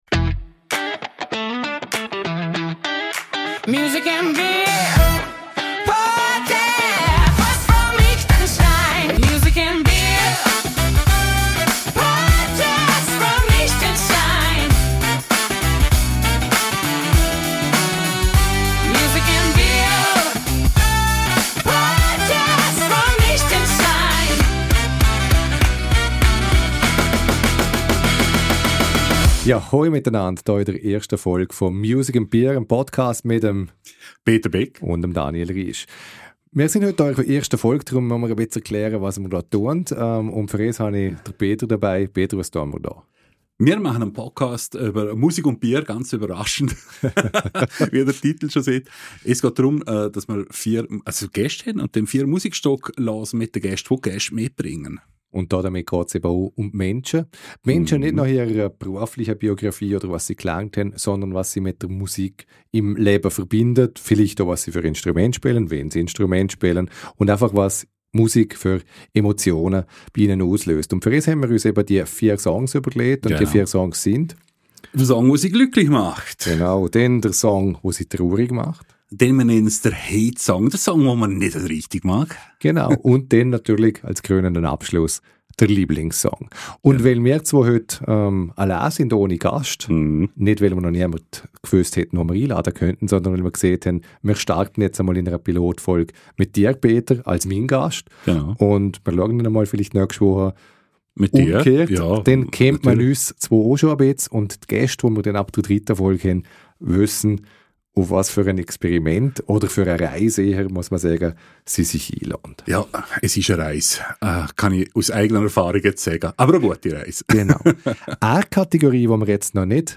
Ein Gast, vier Songs und dazu ein Bier: Die perfekten Voraussetzungen für ein gutes Gespräch über Musik und das Leben.